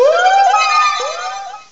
cry_not_lumineon.aif